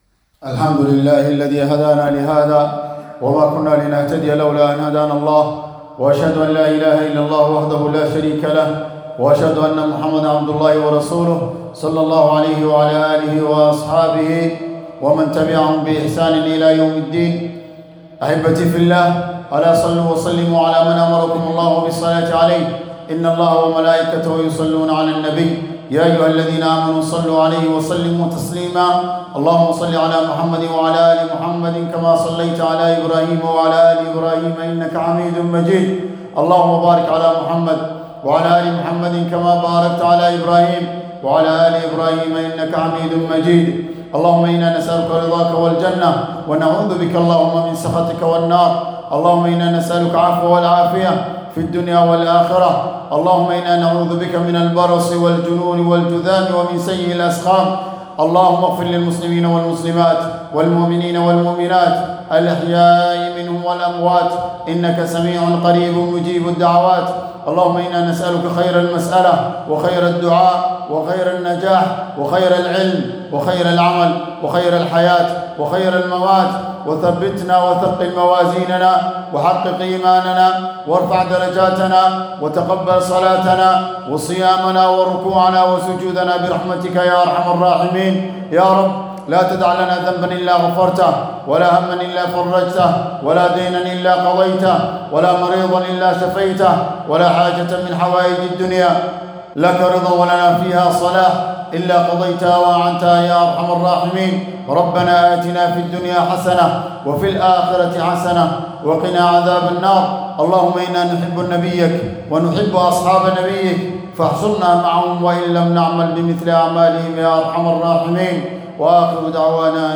الخطبة الثانية
Second Ceremony